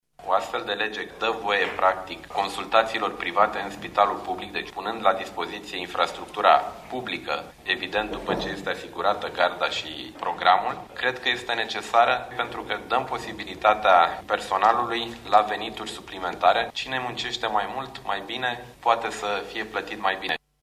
Anunțul a fost făcut de ministrul sănătății, Nicolae Bănicioiu, la finalul ședinței de guvern de azi.